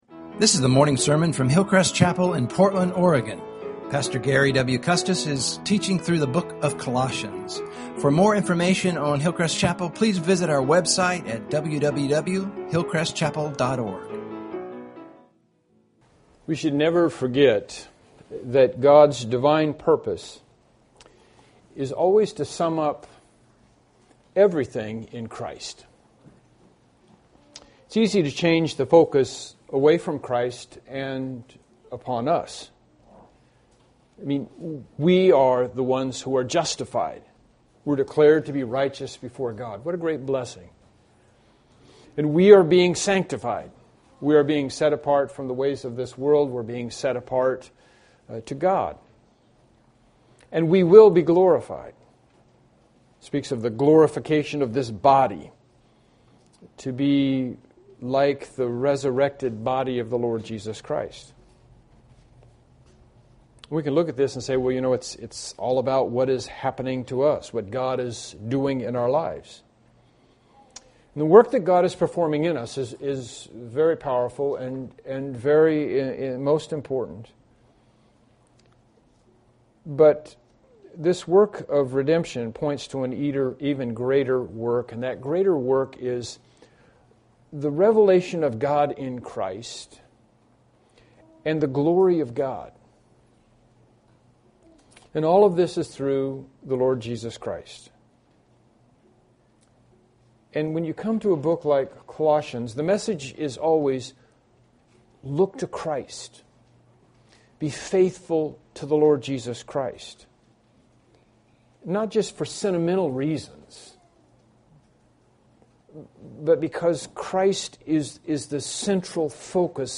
Colossians 2:16-17 Service Type: Morning Worship Service « “Debt and Forgiveness” “The Sovereign Supply” »